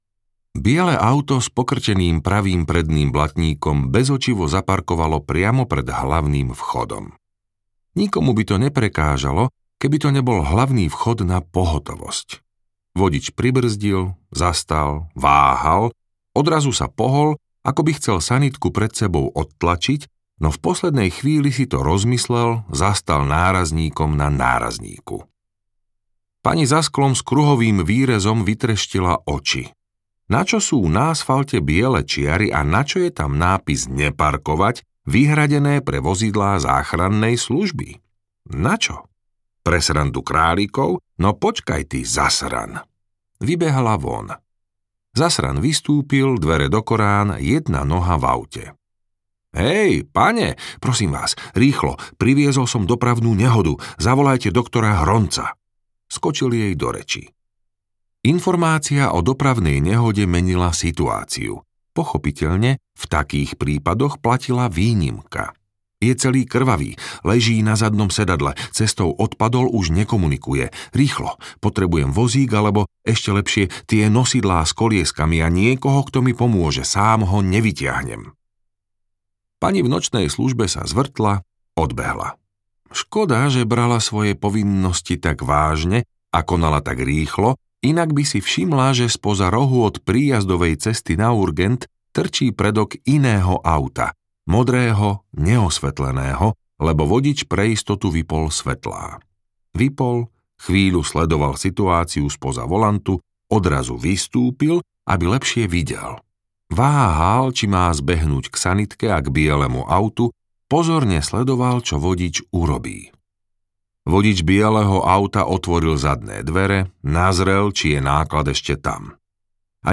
Podaj prst audiokniha
Ukázka z knihy
podaj-prst-audiokniha